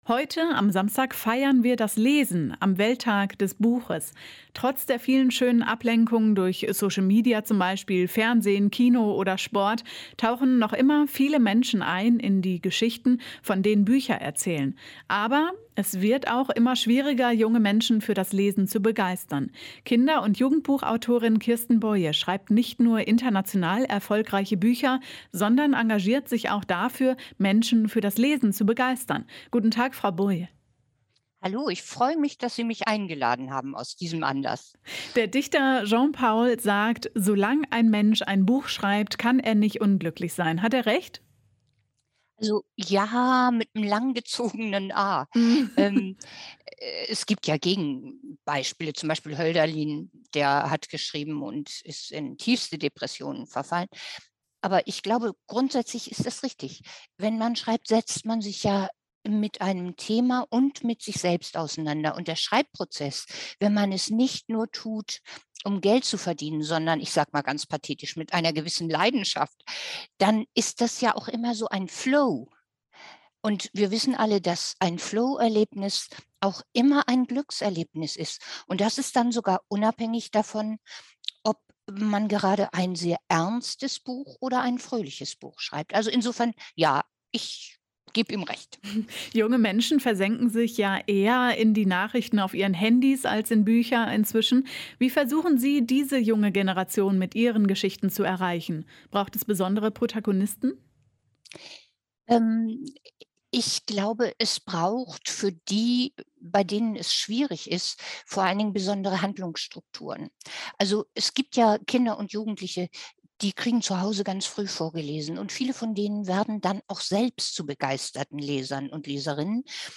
Ein Interview mit Kirsten Boie (Jugendbuchautorin)
Welche Geschichten begeistern junge Menschen und welche Bücher können die Welt verändern? Darüber haben wir mit der Jugendbuchautorin Kirsten Boie gesprochen.